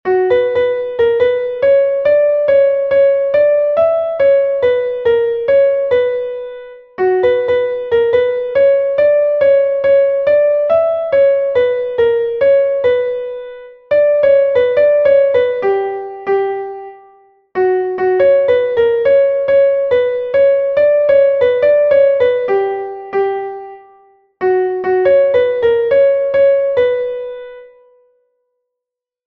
Dantzakoa
8 / 8A / 8 / 8A (hg) | 16A / 16A (ip)